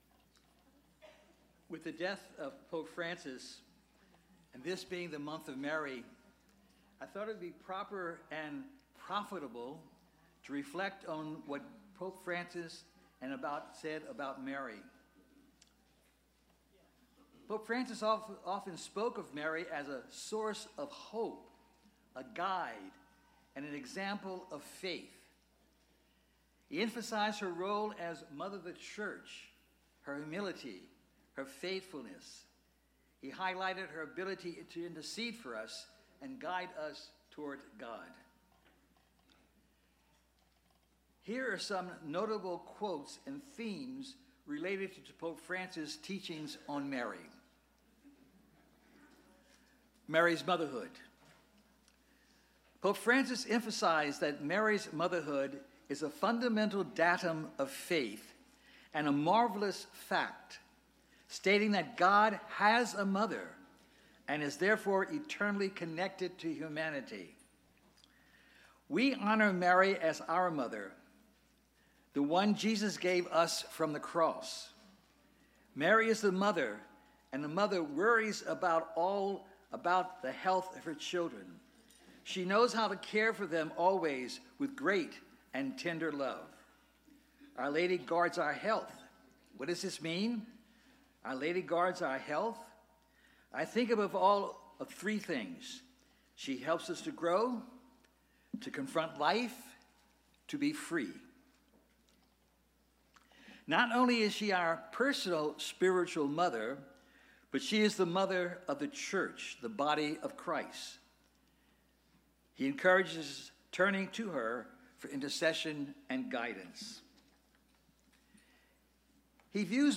Bishop Emeritus Sam Jacobs gives a teaching on Prayer, Fasting and Almsgiving.